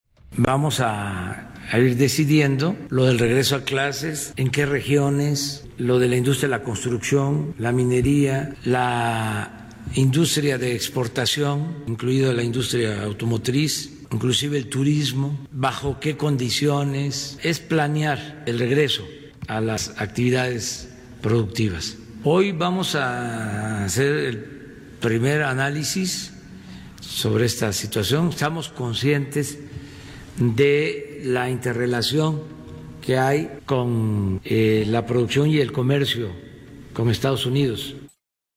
En conferencia de prensa, el Presidente Andrés Manuel López Obrador informó del encuentro que será en Palacio Nacional con los Secretarios de Economía, Salud, Trabajo, Relaciones Exteriores y Educación; así como con el subsecretario de Prevención y Promoción de la Salud, Hugo López-Gatell.